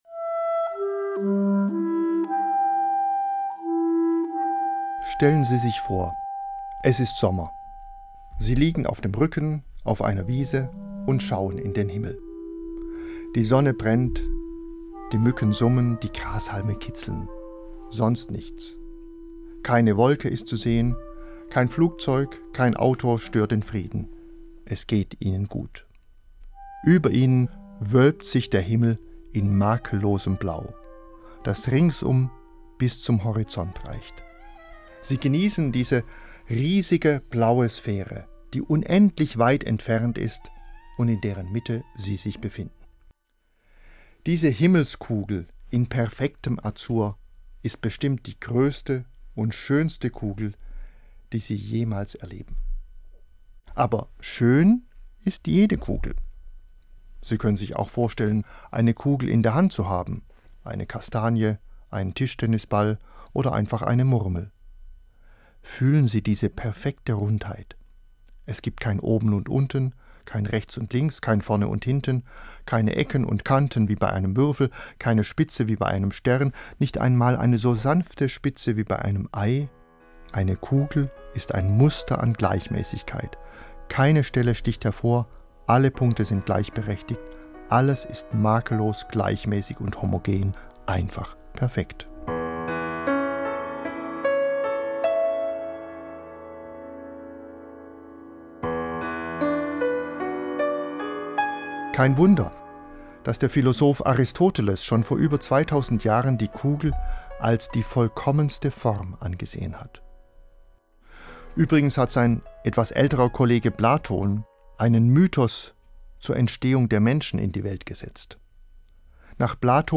Albrecht Beutelspacher erzählt aus der Geschichte der Mathematik: Die Kugel